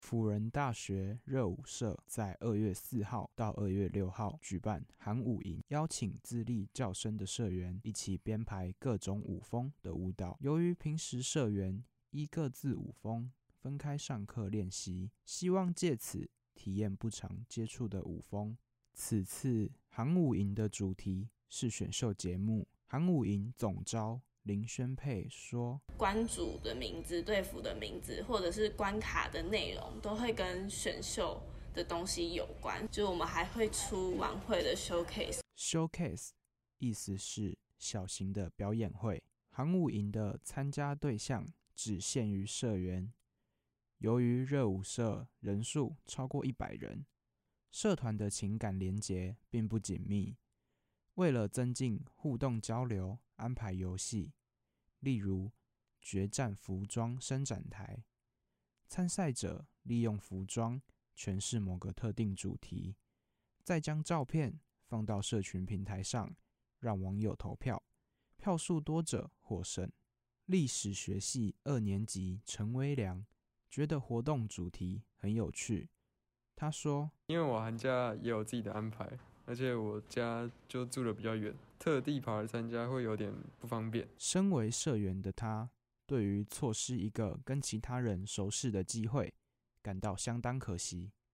熱舞　新聞.mp3